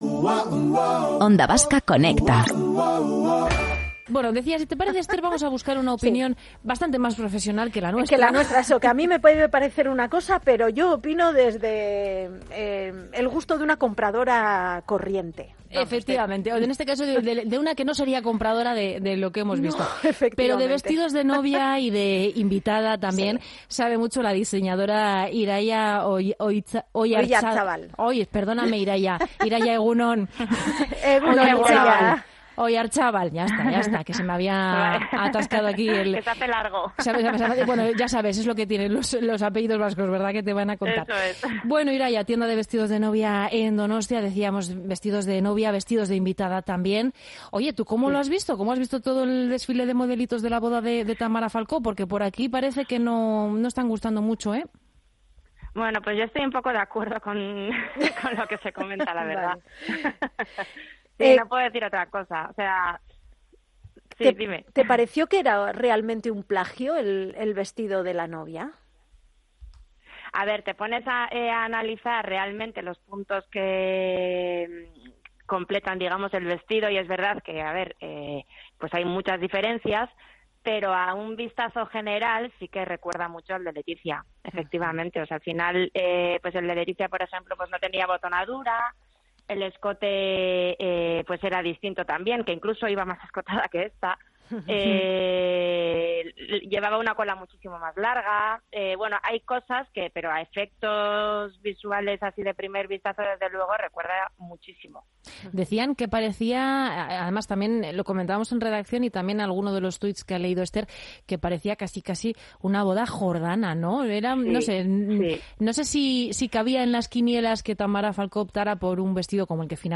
Entrevistada en Onda Vasca